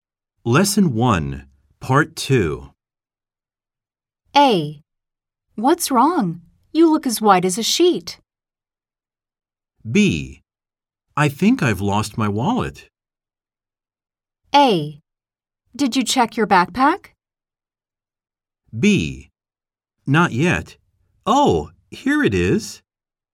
*実際の『いいずなボイス』アプリ・音声CDでは，書き取りをするために音の流れない無音の時間がありますが，このサンプル音声では省略しています。